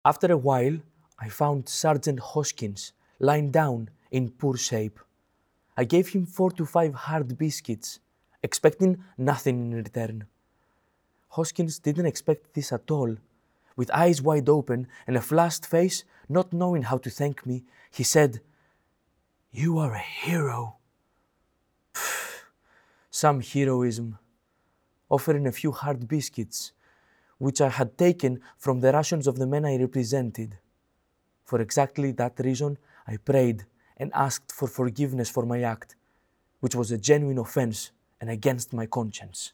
Audio dramatisation based on the Memoirs of a Prisoner.